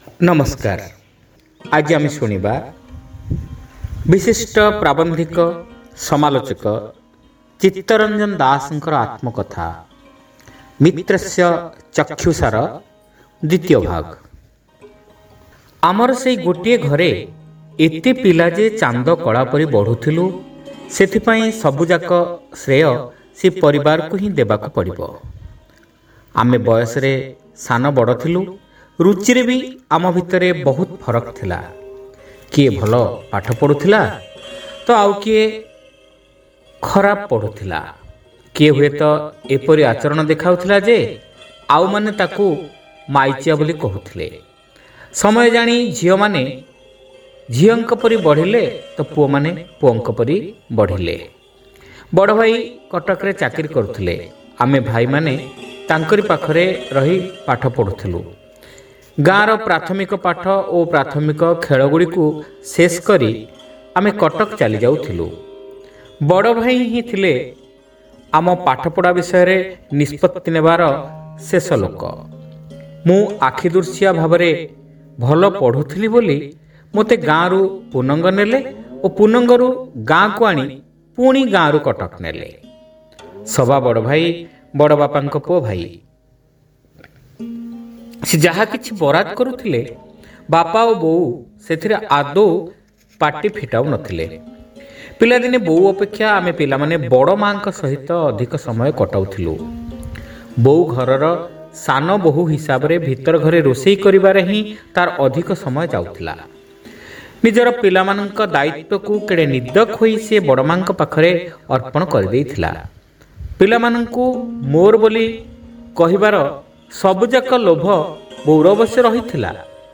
Audio Story : Mitrasya Chakshyusa (Part-2)